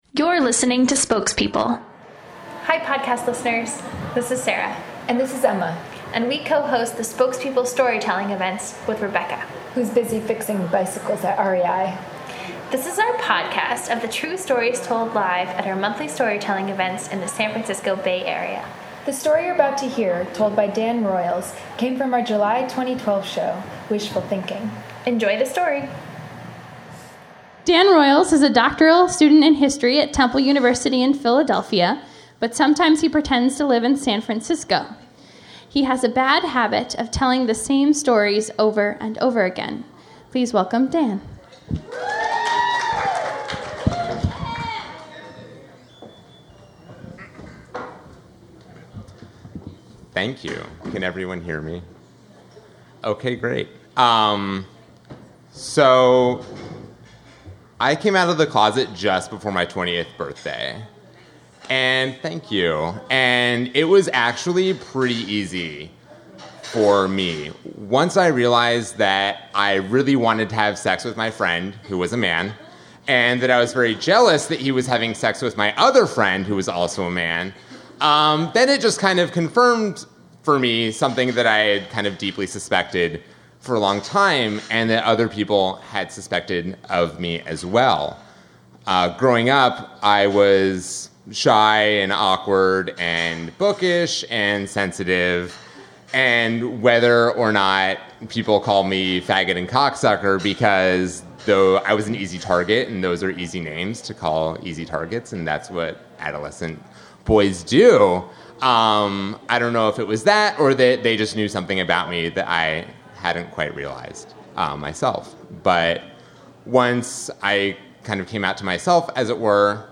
Homepage / Podcast / Storytelling
This story of college love comes from our July show “Wishful Thinking.”